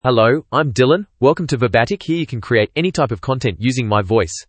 MaleEnglish (Australia)
Dylan is a male AI voice for English (Australia).
Voice sample
Listen to Dylan's male English voice.
Dylan delivers clear pronunciation with authentic Australia English intonation, making your content sound professionally produced.